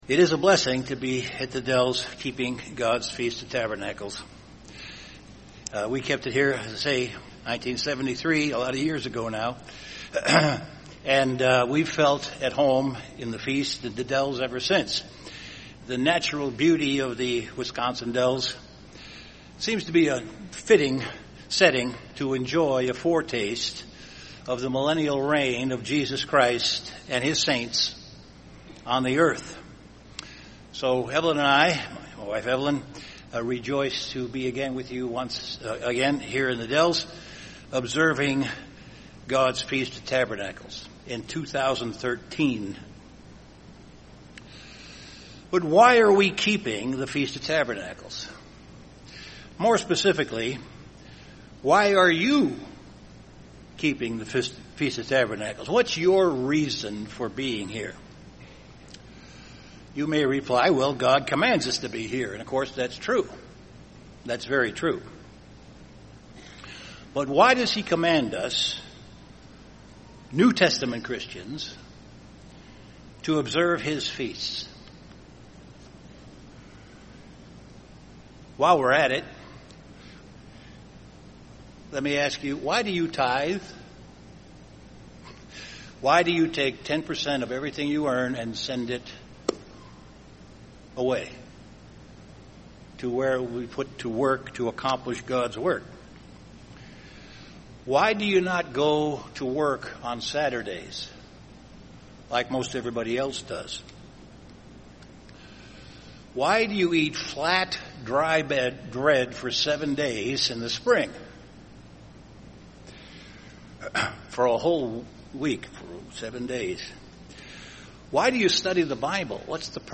This sermon was given at the Wisconsin Dells, Wisconsin 2013 Feast site.